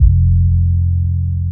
Still Serving 808 - F.wav